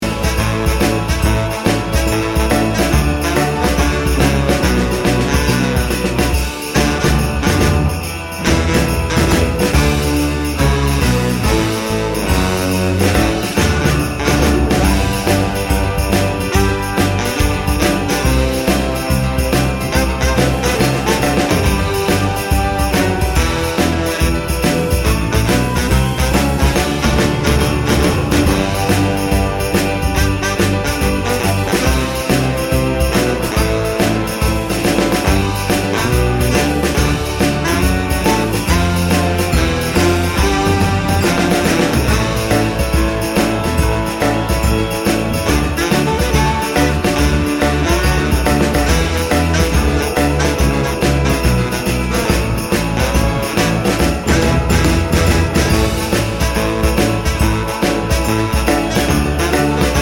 Key of G Major Edit 2 Christmas 3:05 Buy £1.50